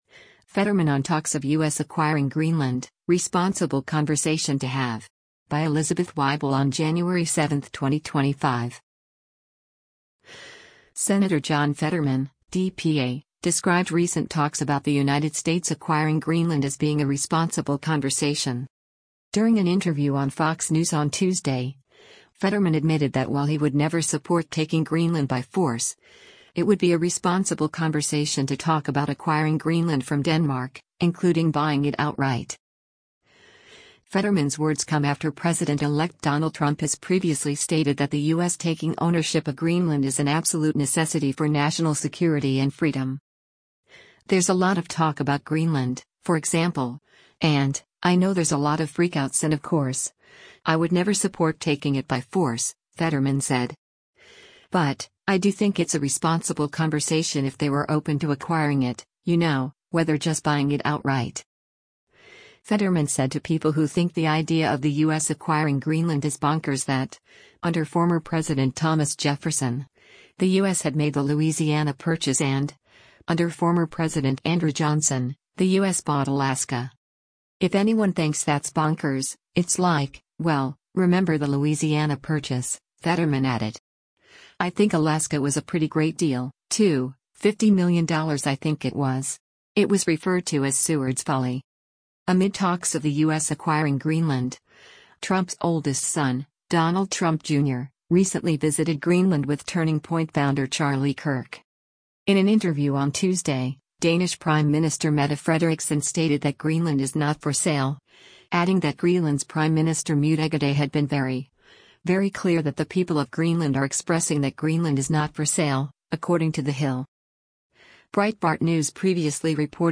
During an interview on Fox News on Tuesday, Fetterman admitted that while he “would never support” taking Greenland “by force,” it would be a “responsible conversation” to talk about acquiring Greenland from Denmark, including “buying it outright.”